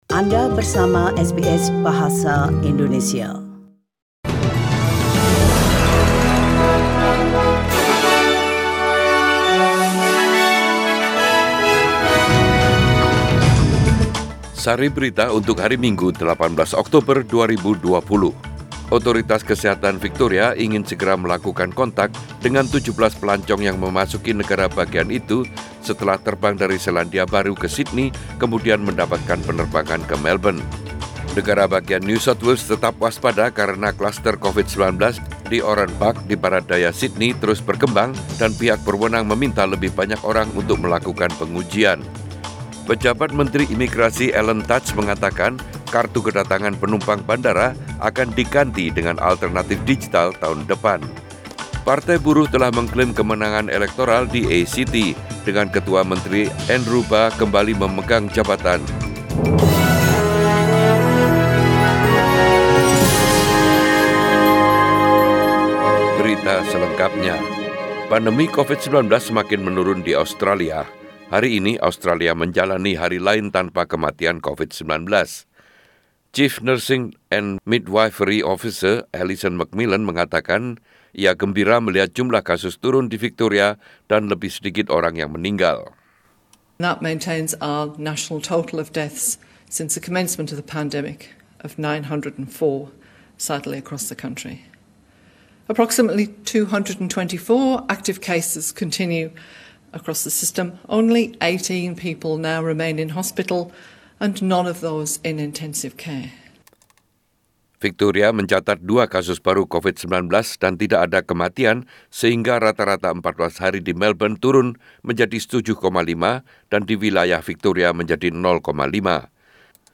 SBS Radio News in Bahasa Indonesia - 18 October 2020